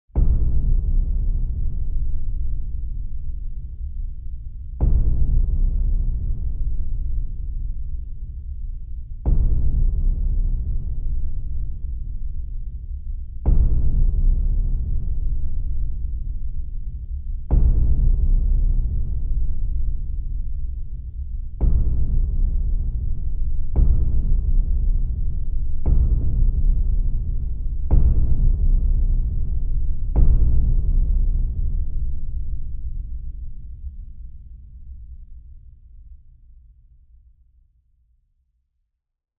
دانلود صدای تصادف 1 از ساعد نیوز با لینک مستقیم و کیفیت بالا
جلوه های صوتی
برچسب: دانلود آهنگ های افکت صوتی حمل و نقل دانلود آلبوم صدای تصادف از افکت صوتی حمل و نقل